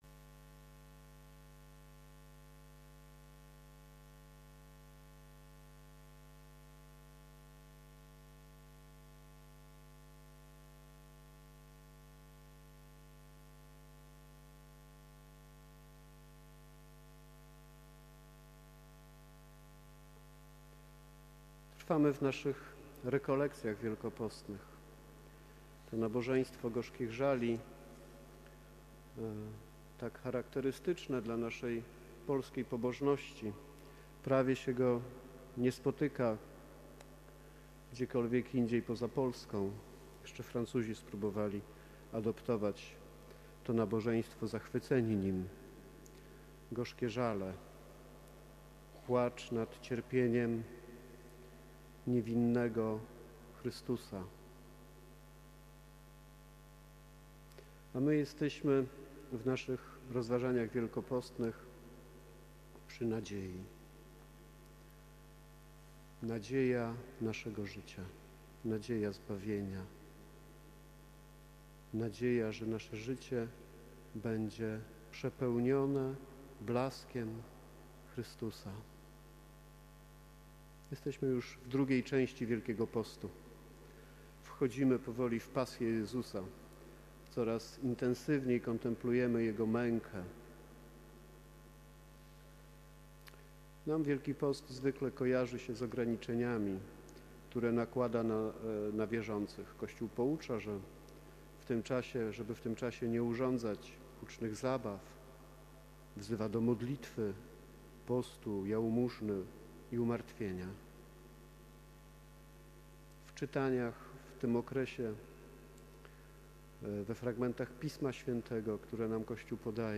Konferencja Rekolekcyjna – Gorzkie Żale | 06.04.2025